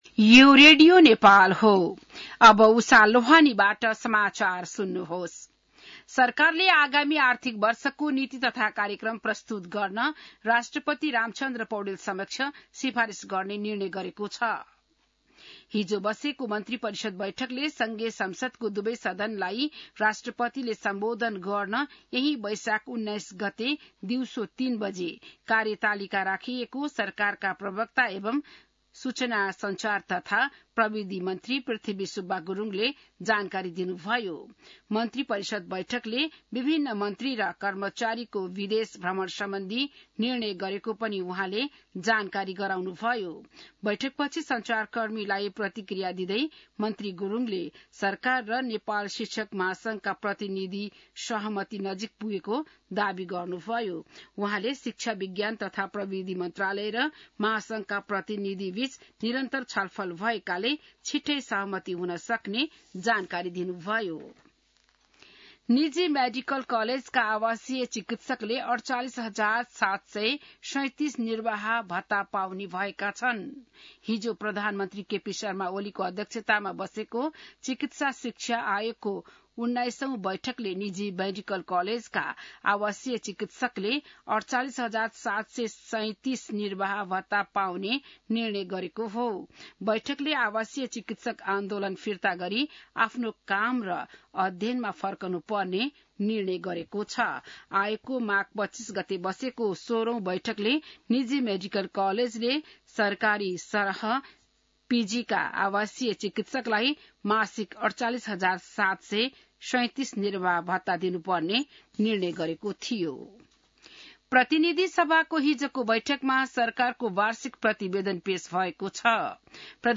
बिहान १० बजेको नेपाली समाचार : १६ वैशाख , २०८२